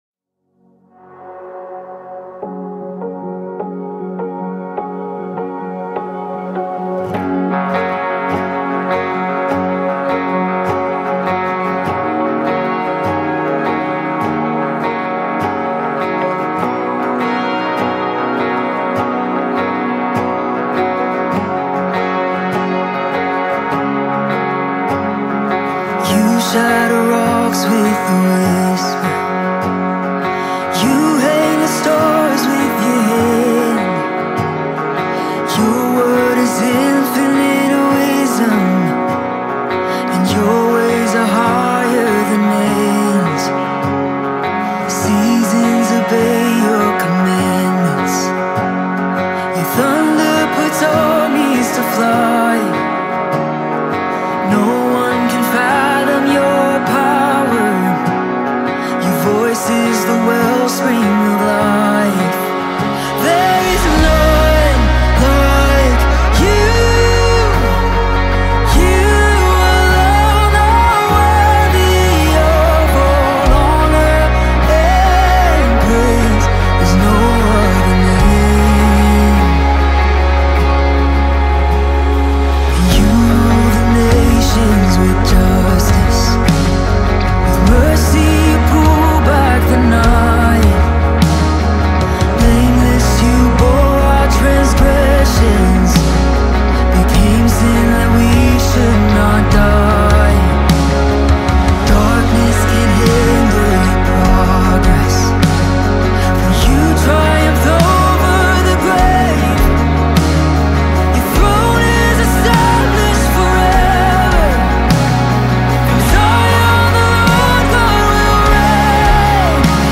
Gospel Songs
If You’re a lover of good and great Gospel/Christian music